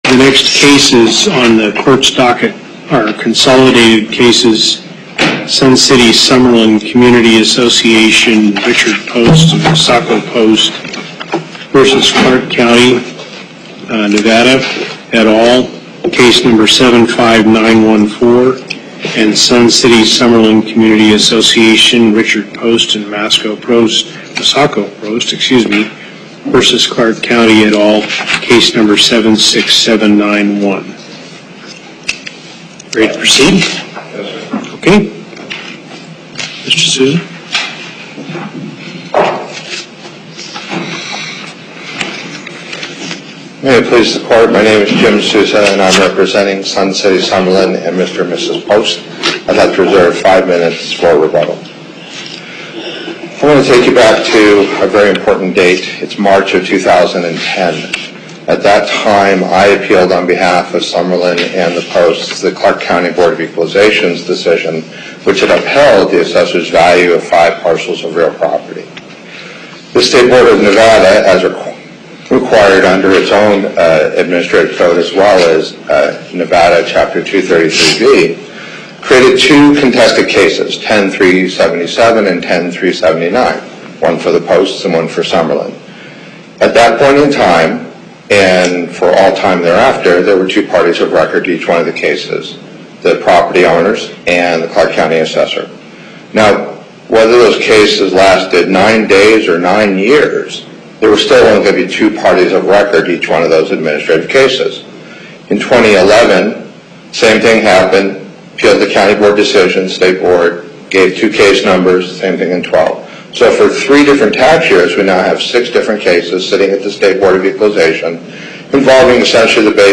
Before the Southern Nevada Panel, Justice Hardesty Presiding